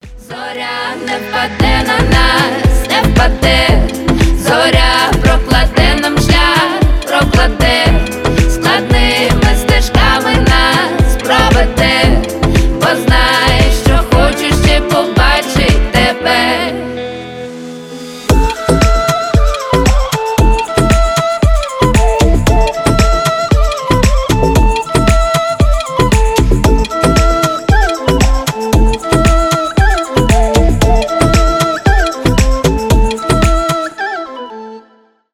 поп
фолк